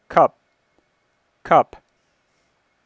Transcription Practice:  English Dialects and Allophones